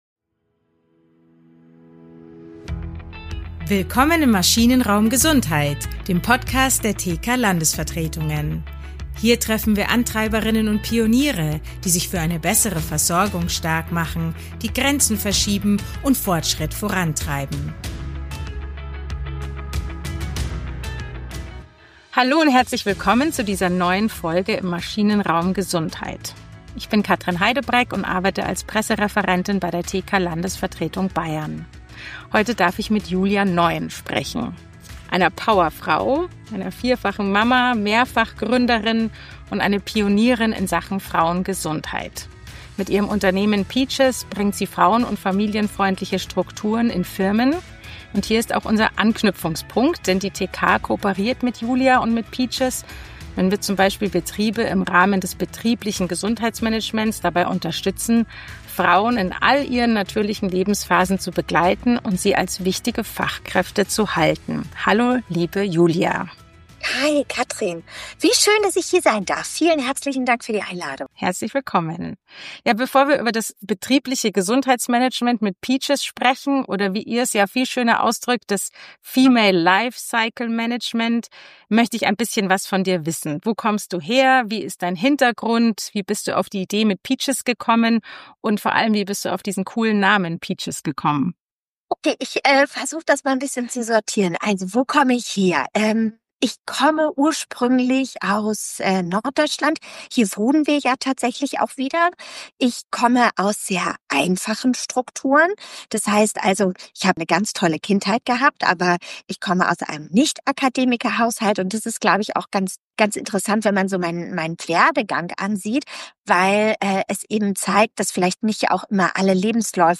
Schluss mit Tabus! Oder: Wie geht Female Lifecycle Management? Ein Gespräch